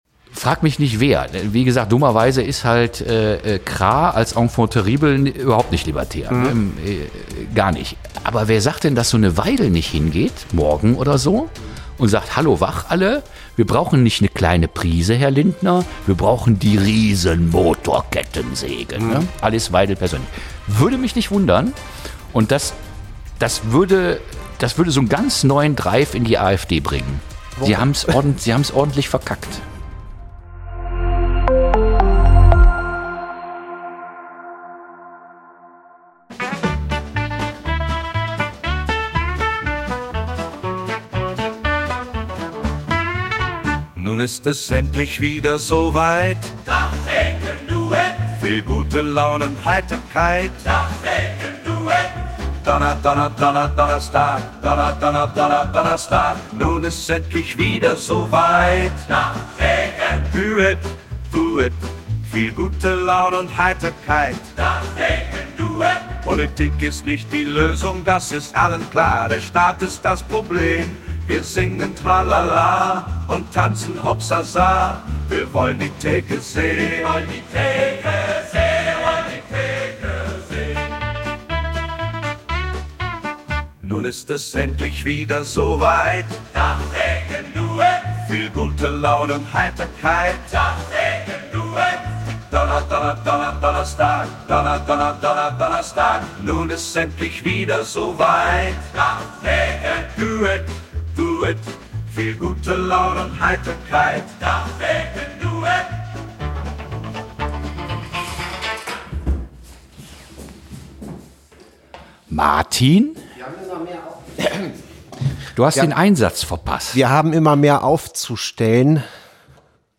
Kleine Redaktionskonferenz am Tresen
Wie immer werden in dieser Reihe an der ef-Theke spontan aktuelle Entwicklungen und brennende Themen beleuchtet.